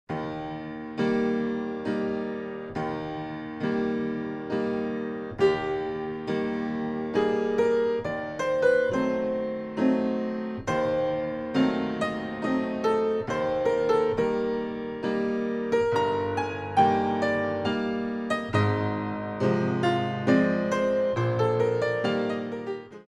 Adagio - Moderato - Allegro - Adagio - Presto